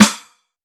Tough Snare.wav